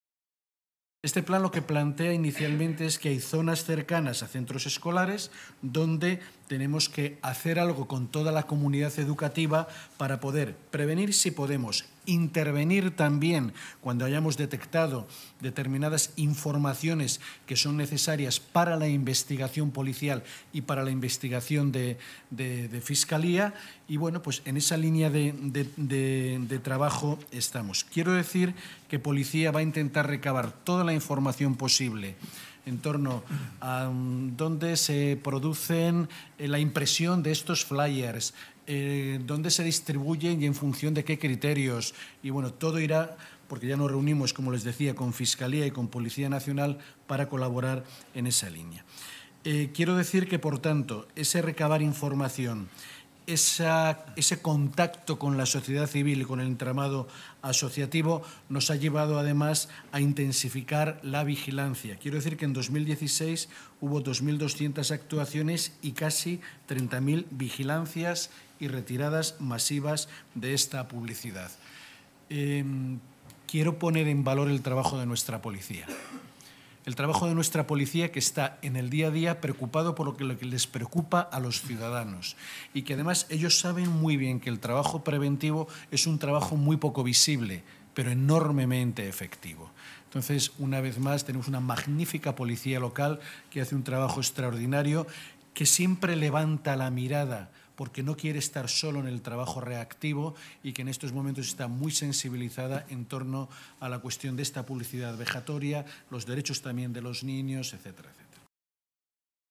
Javier Barbero, delegado del Área de Salud, Seguridad y Emergencias, habla de los objetivos del Plan y destaca la labor de Policía Municipal: